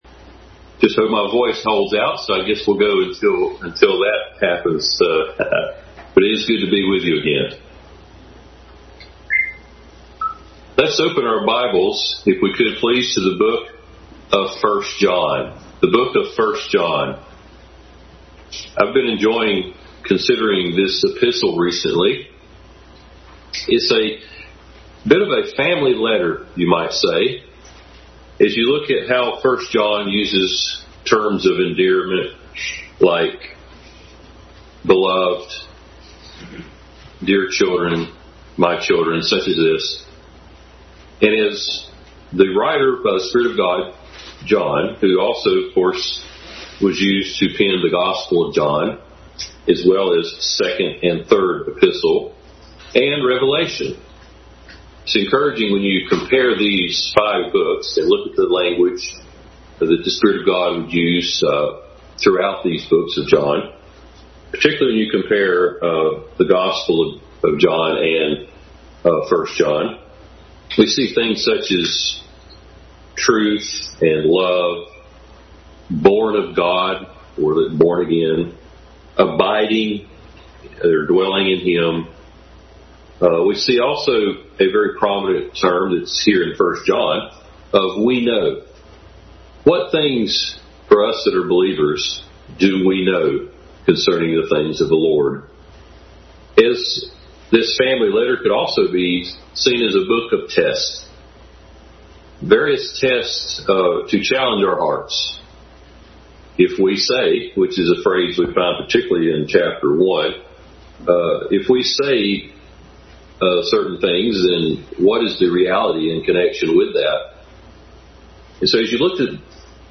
1 John 4:1-19 Passage: 1 John 4:1-11, 2:18-23, 1 Corinthians 2:9-16, 2 Timothy 1:7 Service Type: Family Bible Hour